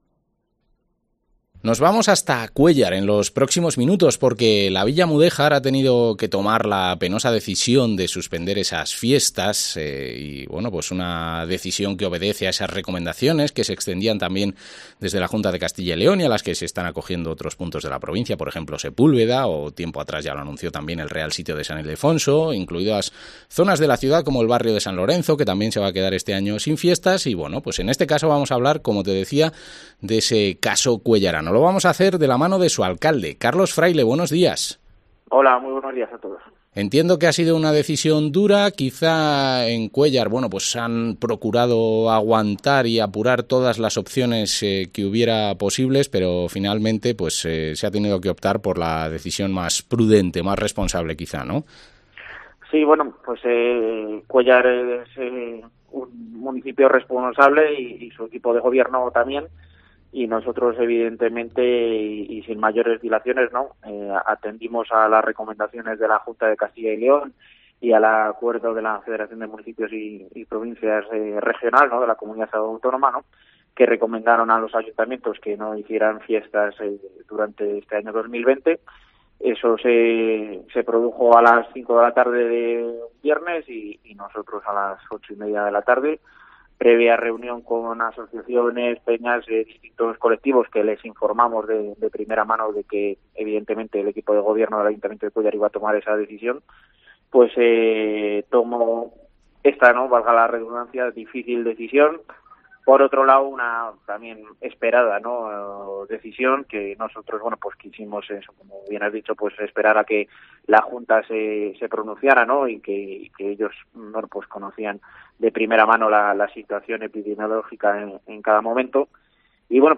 Entrevista al alcalde de Cuéllar, Carlos Fraile